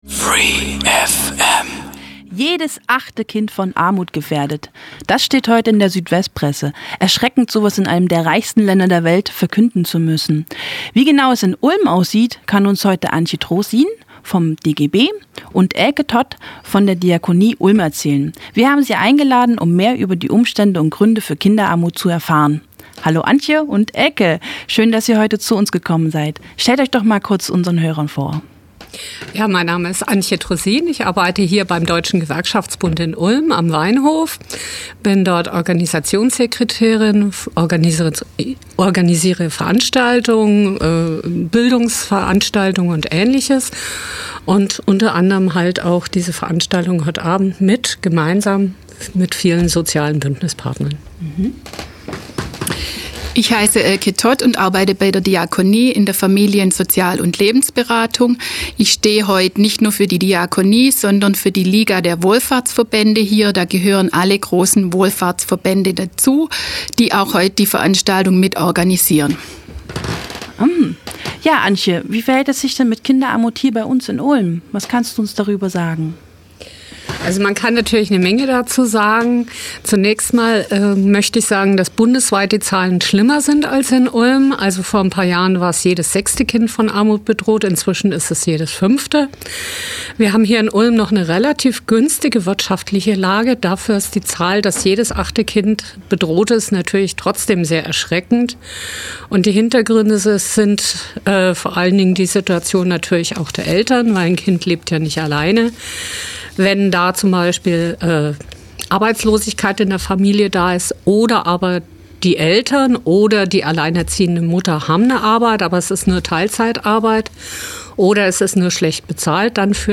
Inteview